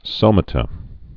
(sōmə-tə)